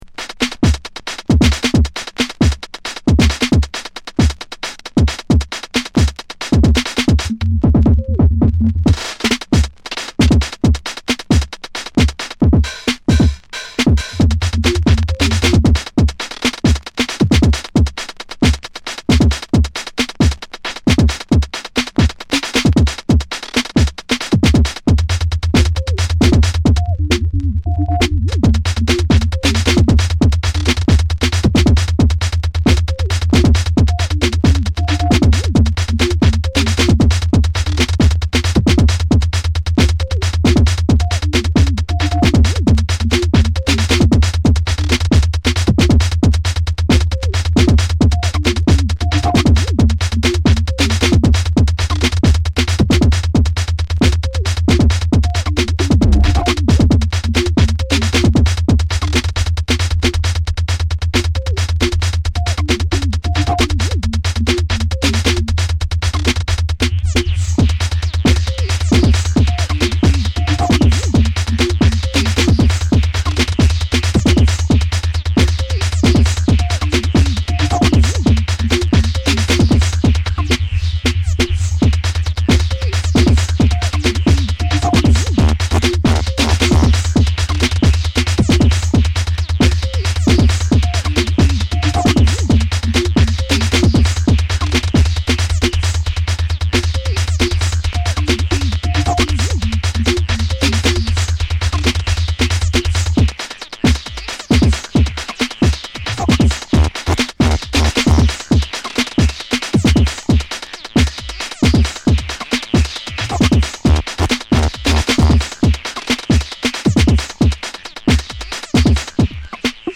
この時代ならではの音使いや豪快な展開、それでいて職人気質すぎる几帳面さも垣間見えるのが長く支持される所以なんでしょうね。